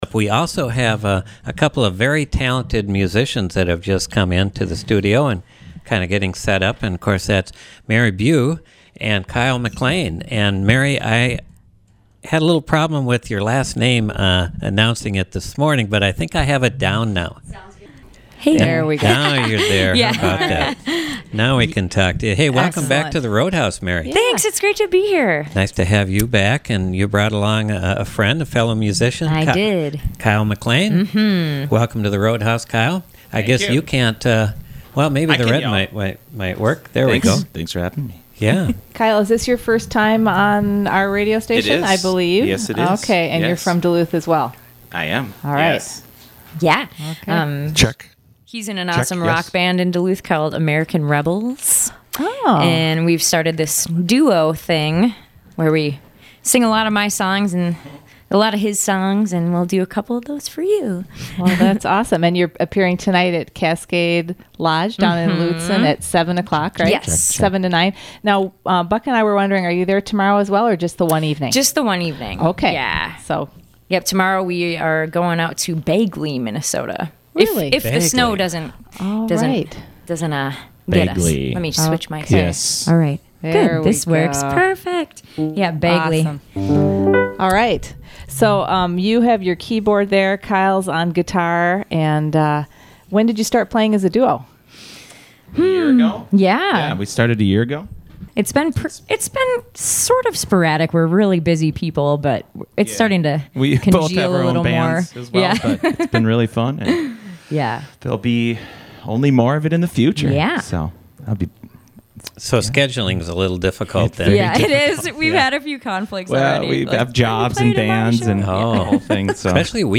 keyboard